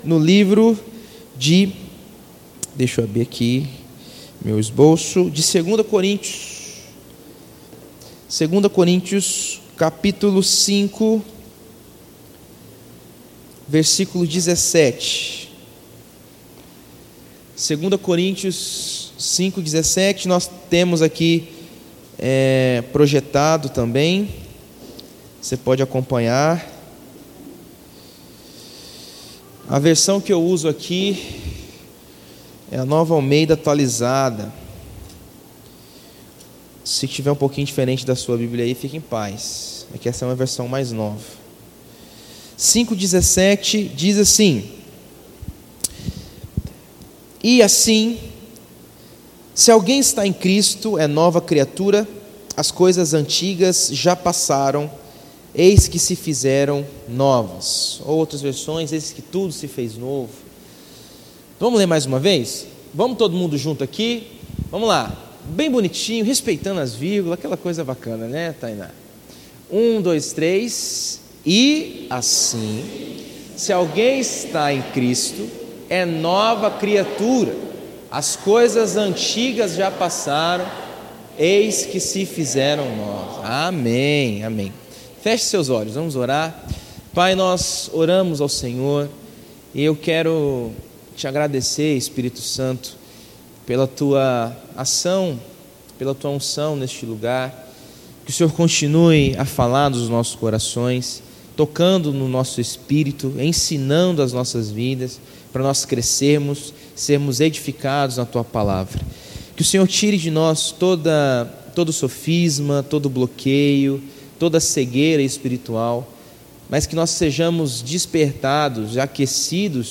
Em Culto de Celebração